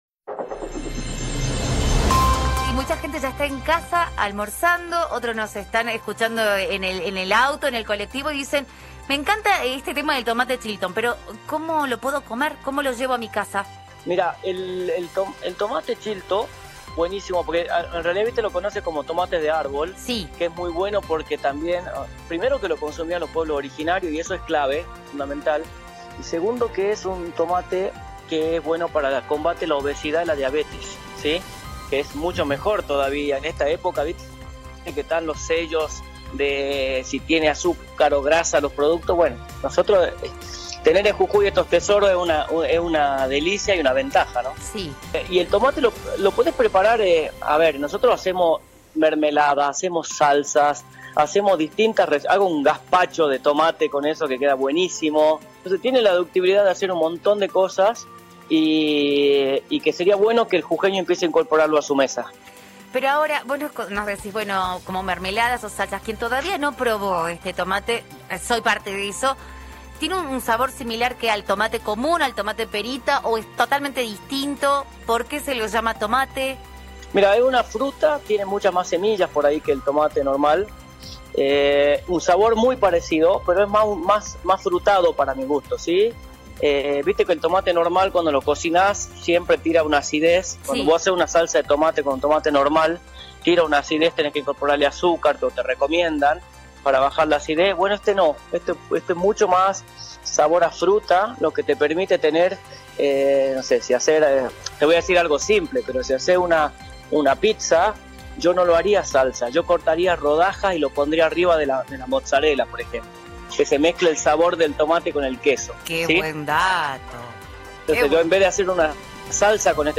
En entrevista con el reconocido chef jujeño